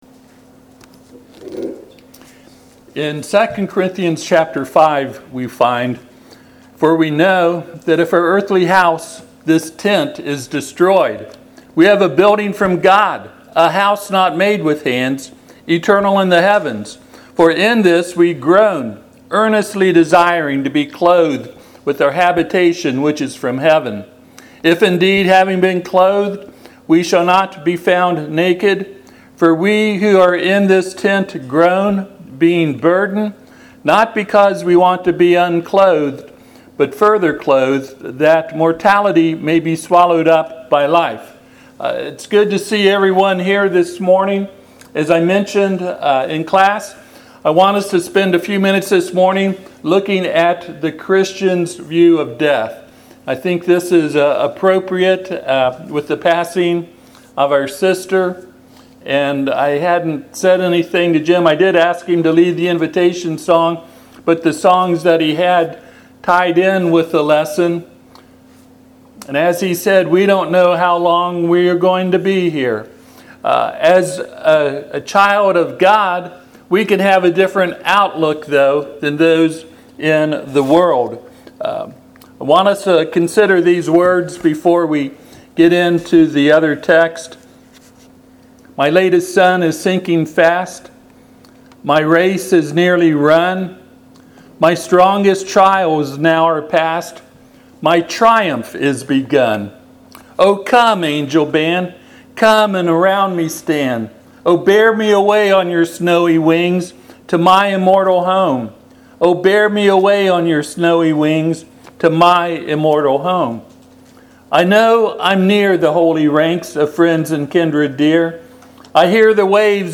Passage: 2 Corinthians 5:1-4 Service Type: Sunday AM